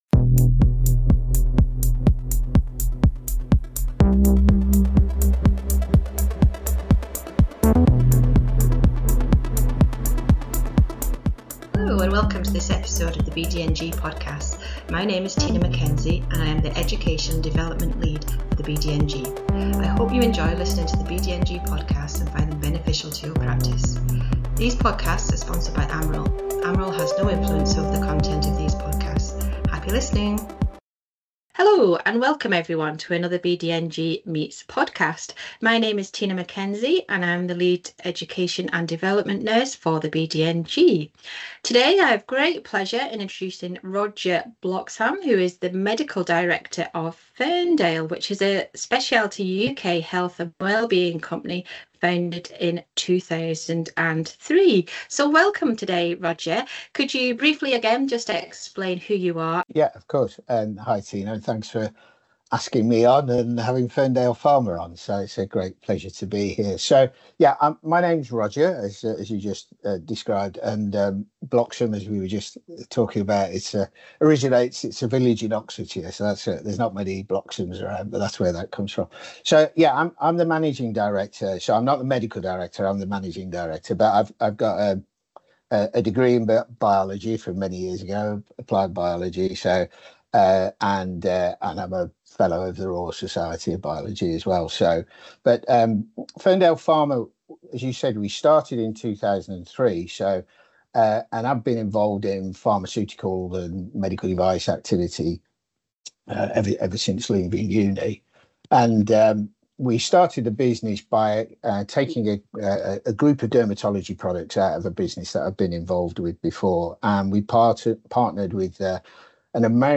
BDNG Podcasts In conversation with …